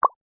button.mp3